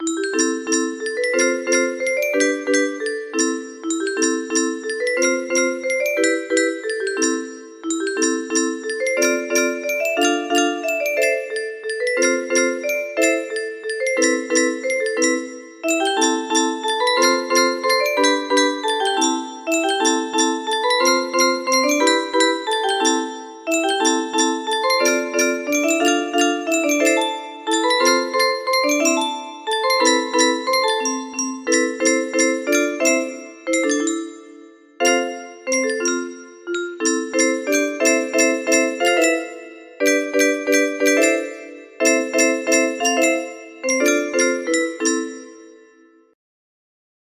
No double notes, full beat chords